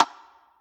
spinwheel_tick_01.ogg